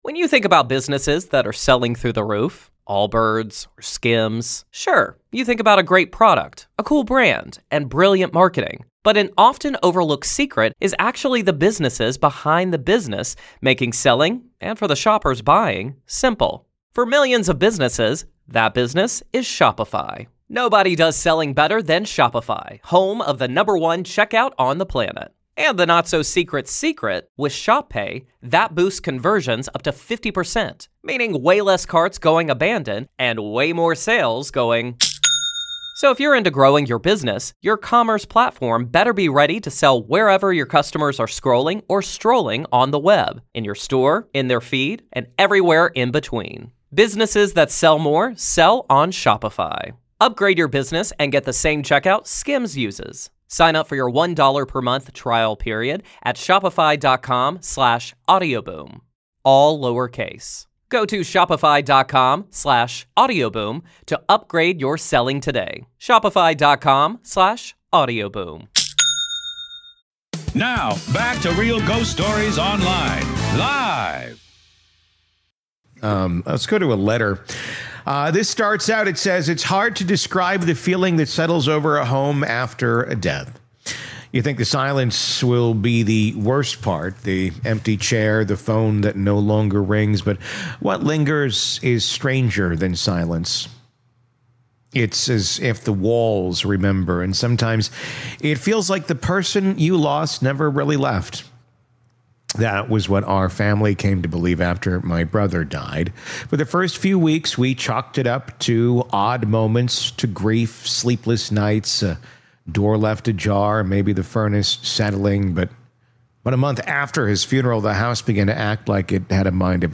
A listener calls in with a truly chilling tale that began after her grandmother passed away in the family home. Enter the brother’s girlfriend—obsessed with seances, dabbling in dark rituals, and warned not to mess with the spirit world.